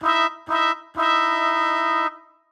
Index of /musicradar/gangster-sting-samples/95bpm Loops
GS_MuteHorn_95-E1.wav